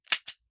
38 SPL Revolver - Dry Trigger 002.wav